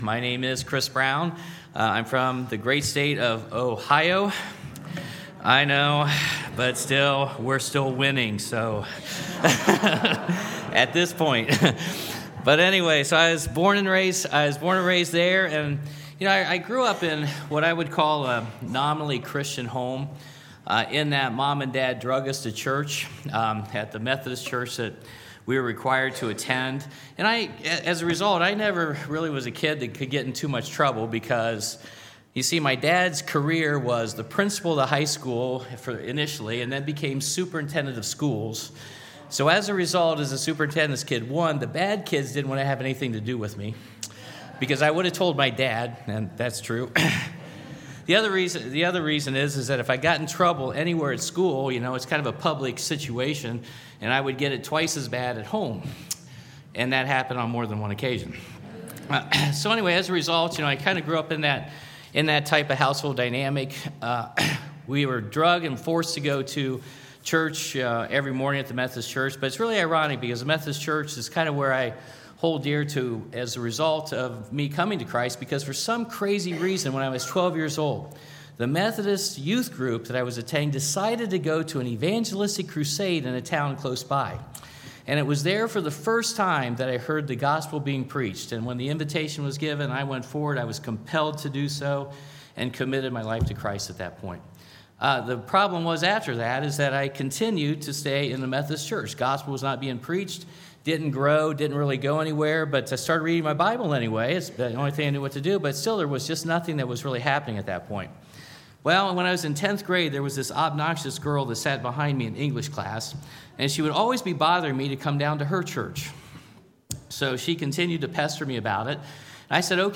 Testimony